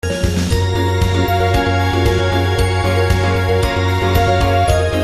オール耳コピ＆少しだけアレンジしています。
あまりアレンジはしていません。
試聴する 5秒間クオリティーを少し下げたものを聞けます。（サイズ60KB）